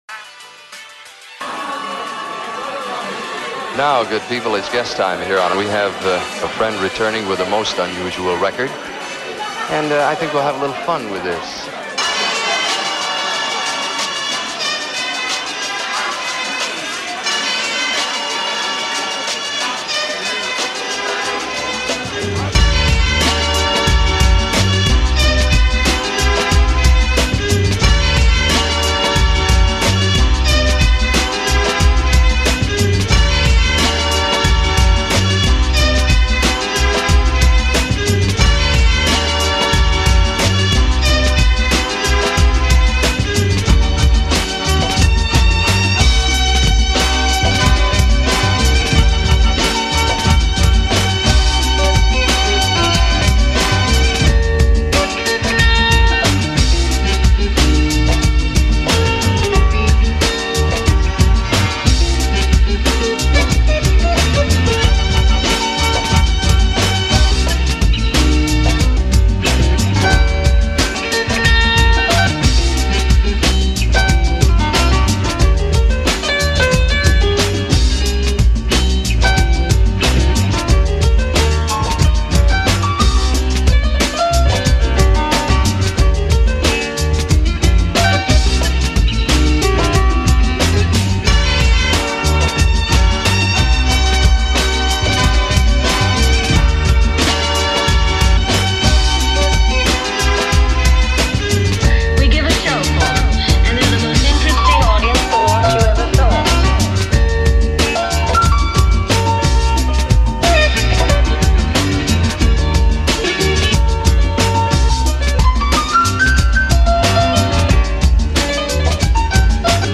experimental electro outfit